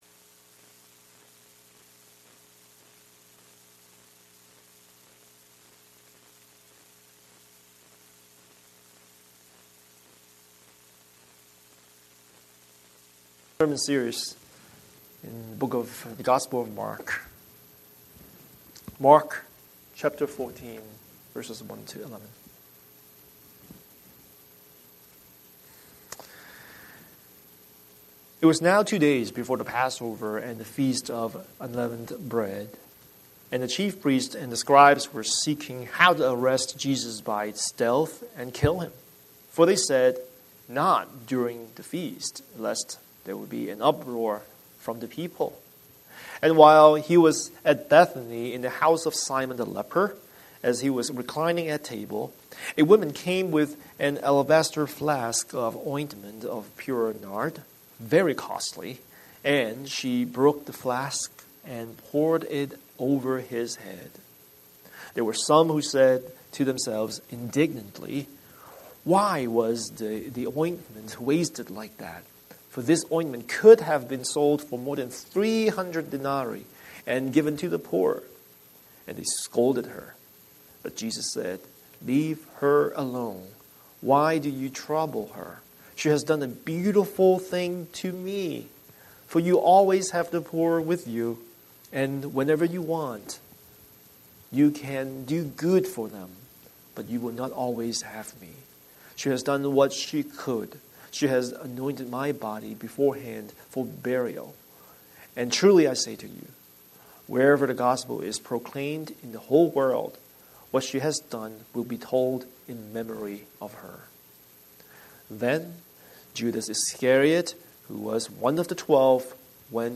Scripture: Mark 14:1-11 Series: Sunday Sermon